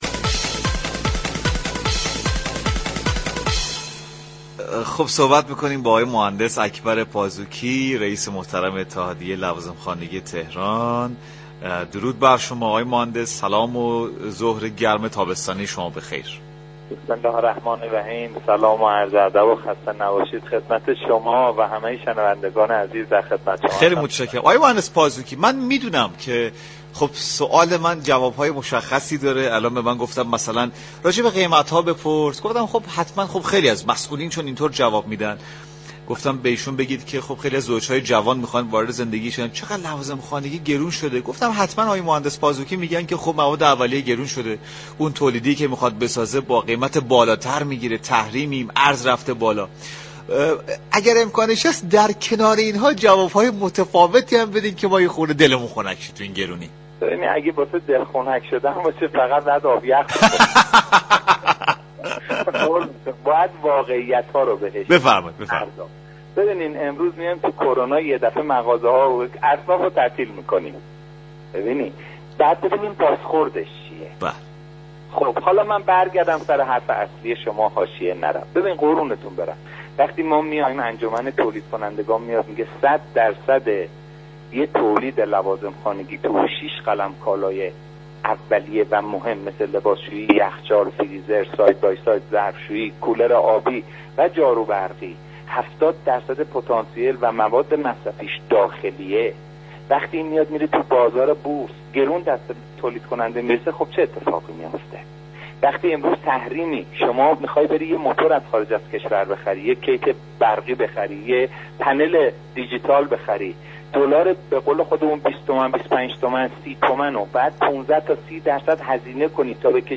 در گفتگو با برنامه سعادت آباد